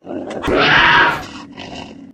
c2_attack_2.ogg